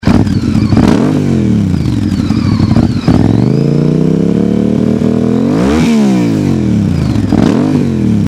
Honda VTR Firestorm - 1000cc. Effektsystem